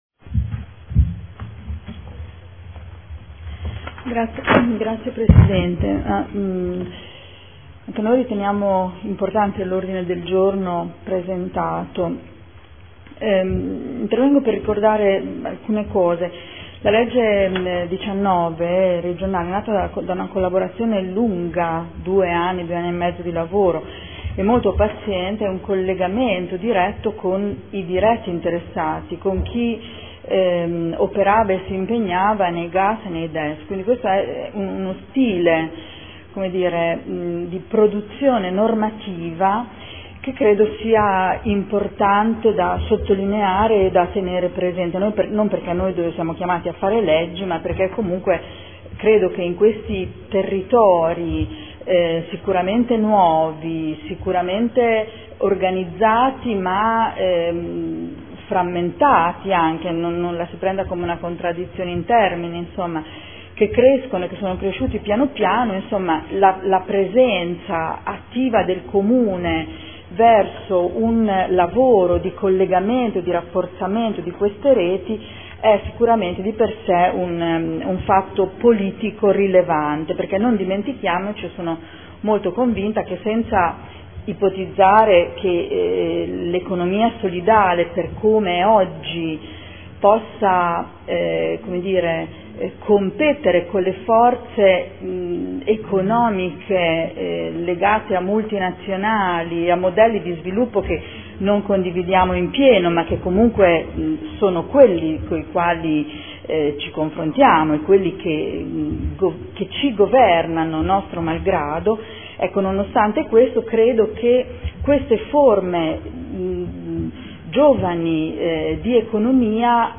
Seduta del 15 gennaio 2015. Ordine del Giorno presentato dai consiglieri Cugusi (SEL), Fasano (P.D.) avente per oggetto: “Economia solidale” - Primo firmatario consigliere Cugusi. Dibattito